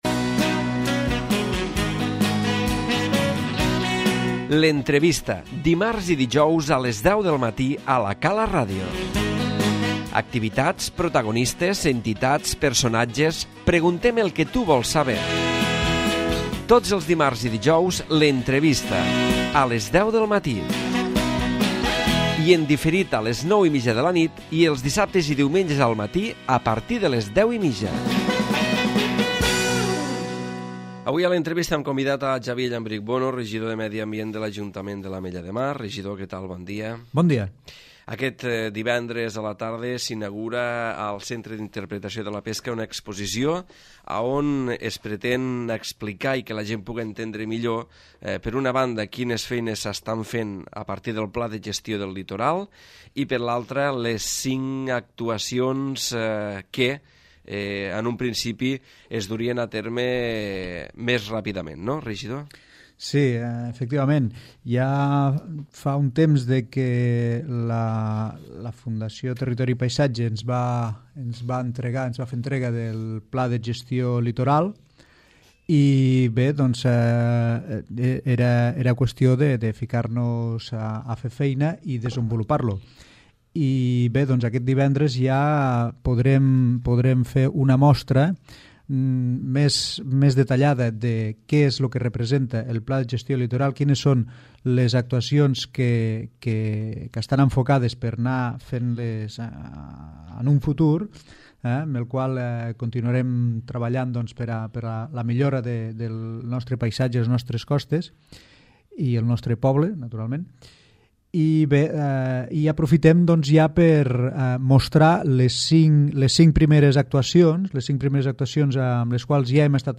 L'Entrevista
El Regidor de Medi Ambient Xavier Llambrich Bono ha estat present a l'entrevista on ha parlat de la inauguració aquest divendres a les 19'30h de l'exposició ''La costa, el nostre fet diferencial'', on es podrà trobar tota la informació sobre el Pla d